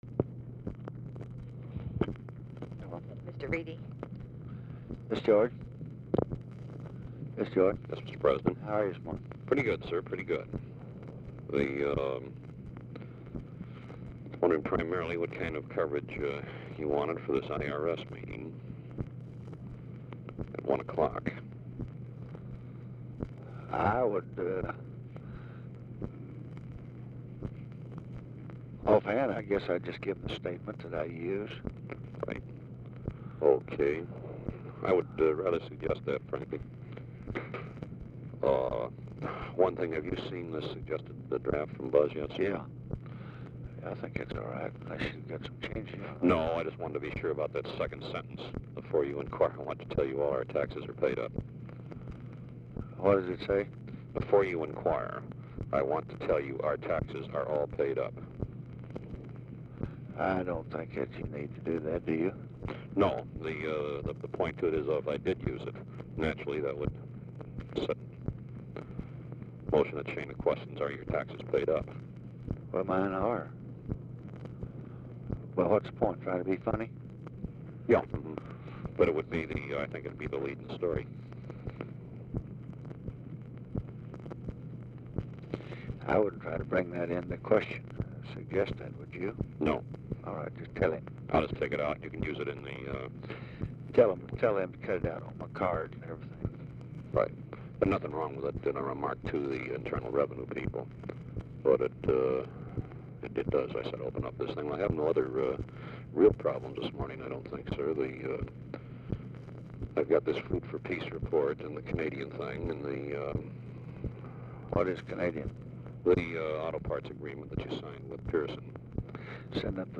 Telephone conversation # 7189, sound recording, LBJ and GEORGE REEDY, 3/31/1965, 10:31AM | Discover LBJ
Format Dictation belt
Location Of Speaker 1 Mansion, White House, Washington, DC
Specific Item Type Telephone conversation